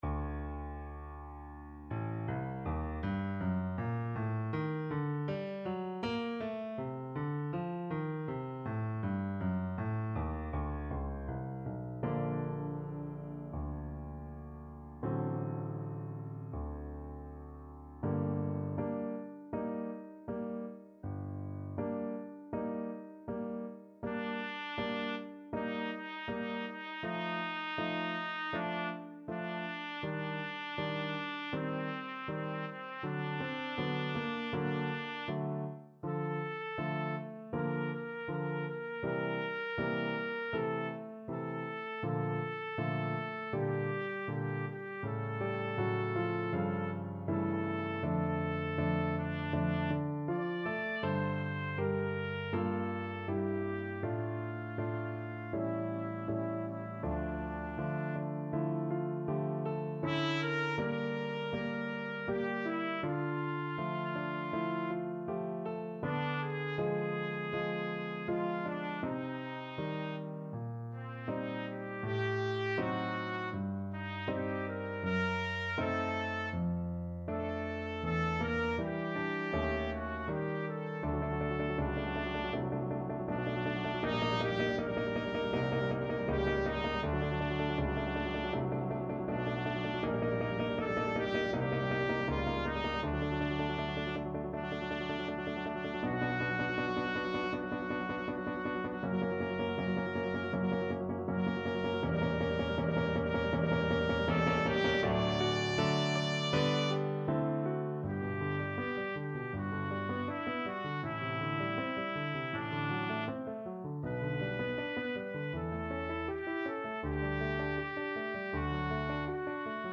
(Repentir) Trumpet version
Trumpet
G minor (Sounding Pitch) A minor (Trumpet in Bb) (View more G minor Music for Trumpet )
~ = 100 Molto moderato =80
Classical (View more Classical Trumpet Music)